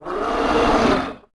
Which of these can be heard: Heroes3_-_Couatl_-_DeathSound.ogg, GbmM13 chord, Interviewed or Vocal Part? Heroes3_-_Couatl_-_DeathSound.ogg